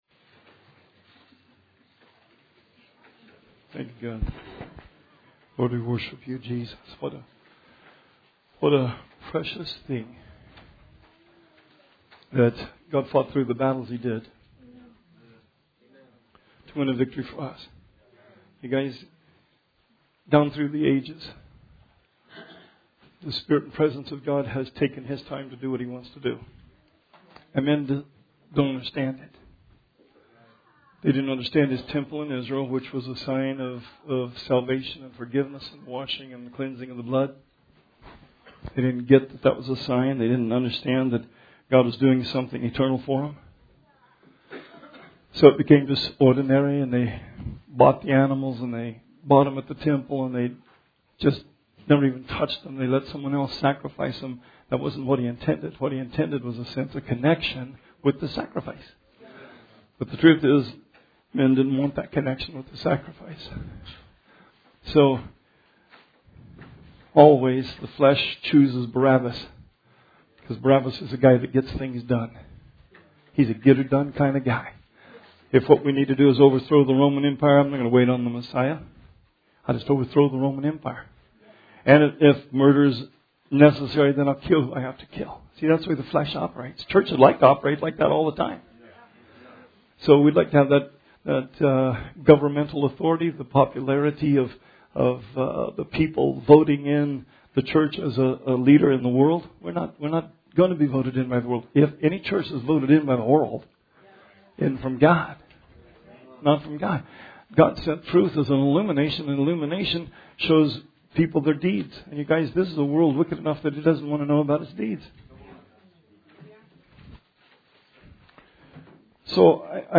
Sermon 4/28/19